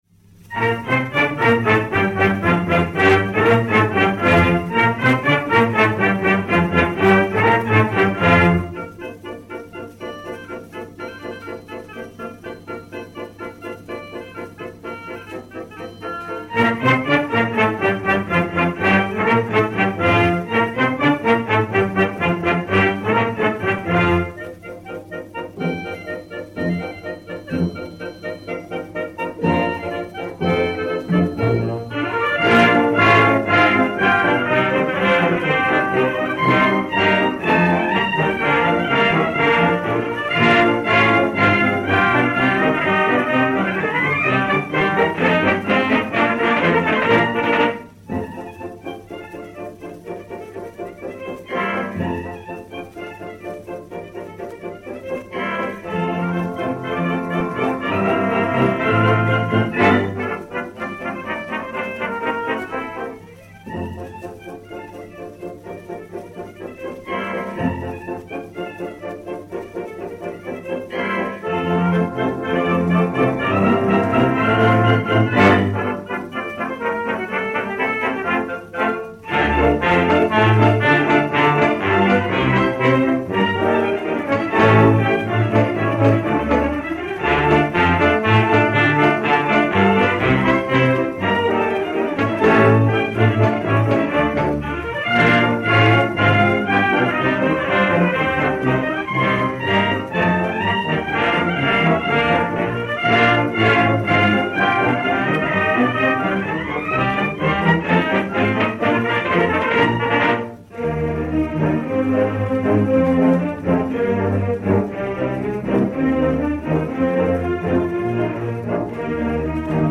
Suite d'orchestre des Deux Pigeons
Orchestre Symphonique dir François Rühlmann